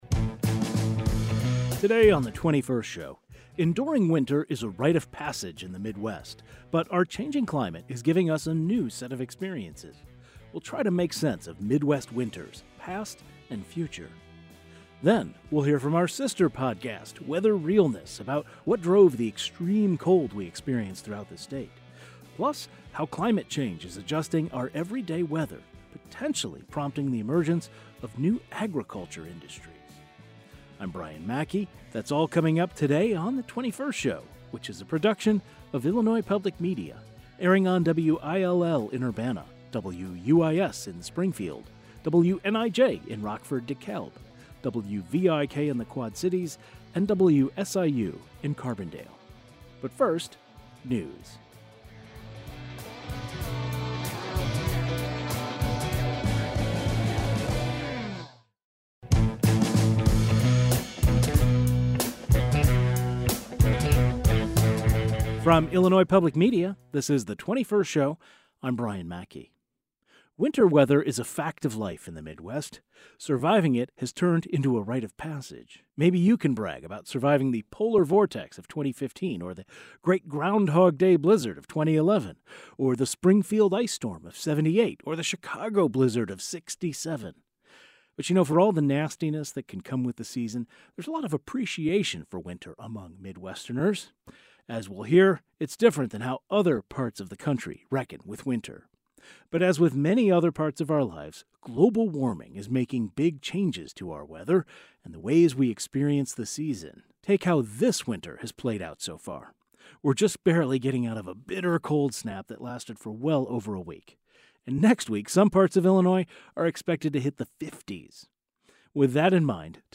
Today's show included a rebroadcast of the following "best of" segment first aired February 26, 2024: Enduring wild winters in the Midwest.
We'll also hear a conversation from our sister podcast Weather Realness about the extreme cold snap we just experienced, and how climate change is opening up new opportunities in agriculture.